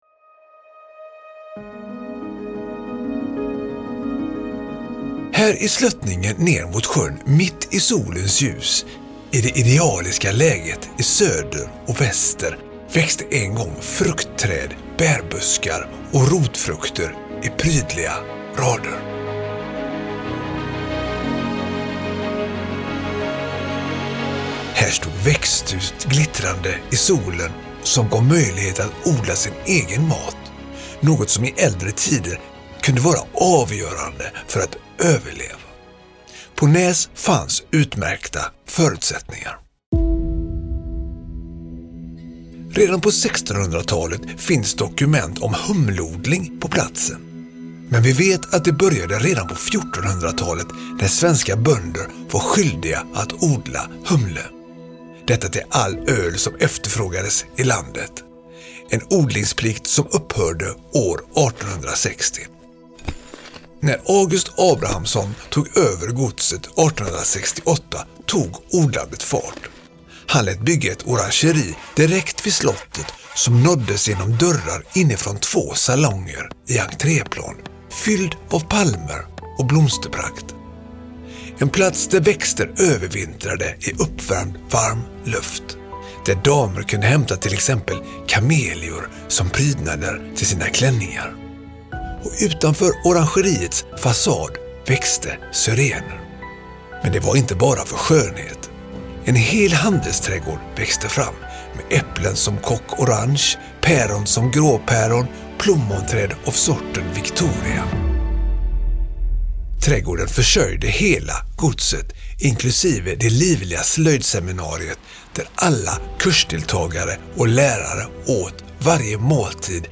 I audioguiden får du höra om August Abrahamson – den siste ägaren av slottet, som gjorde Nääs till ett centrum för hantverk och lärande.